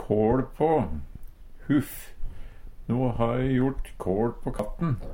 kåL på - Numedalsmål (en-US)